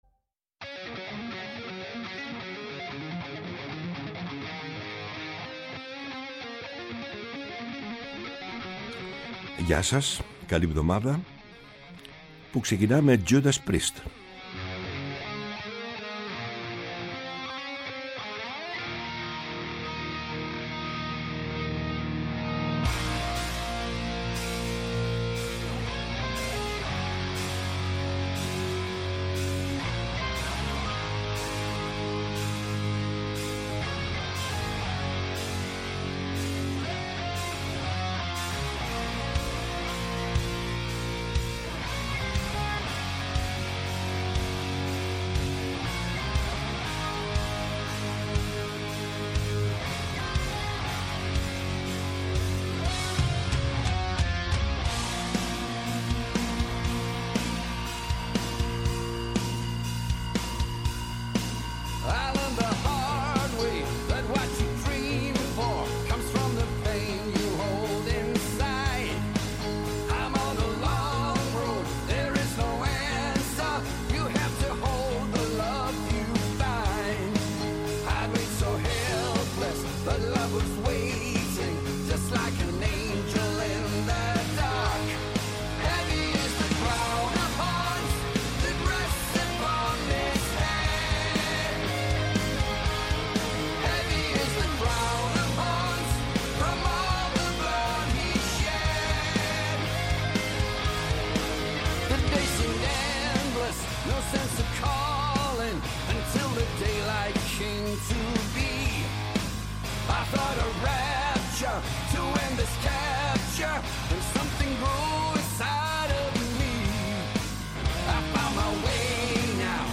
Η μακροβιότερη εκπομπή στο Ελληνικό Ραδιόφωνο!